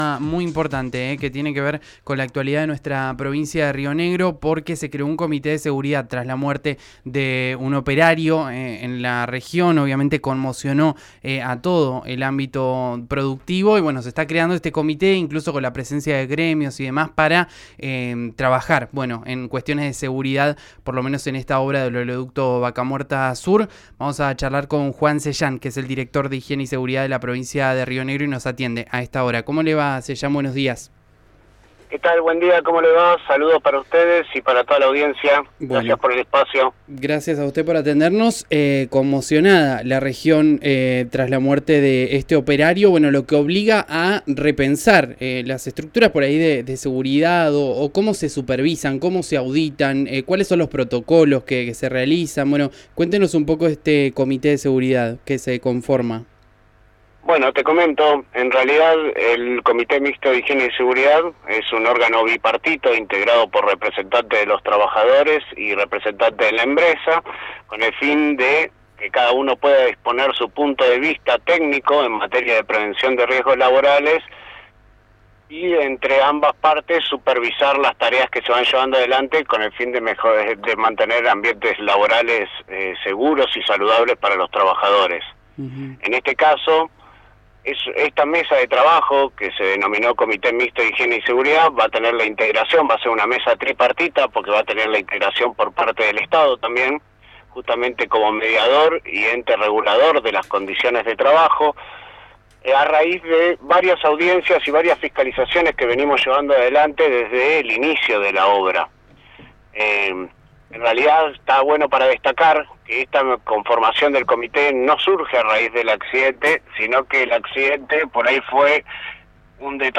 Las precisiones las dio el director de Seguridad e Higiene de la Secretaría de Trabajo, Juan Sellan, en diálogo con RÍO NEGRO RADIO.